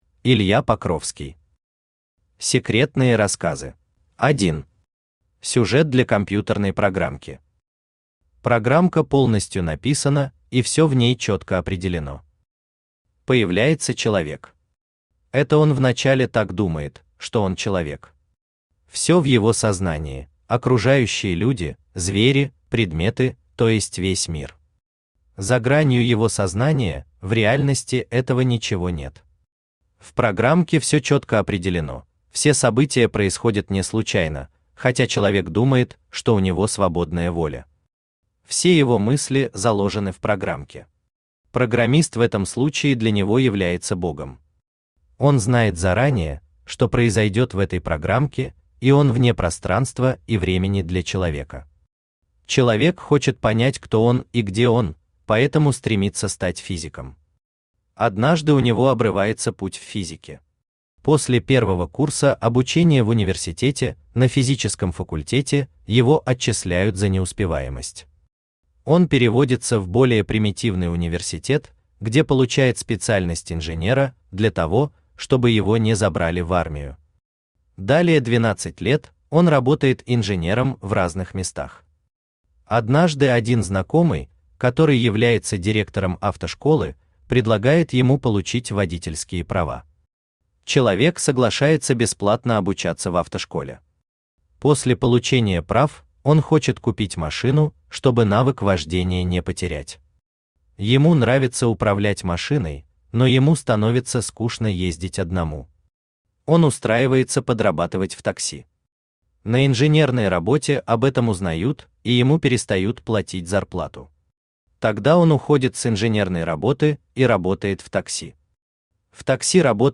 Аудиокнига Секретные рассказы | Библиотека аудиокниг
Aудиокнига Секретные рассказы Автор Илья Вячеславович Покровский Читает аудиокнигу Авточтец ЛитРес.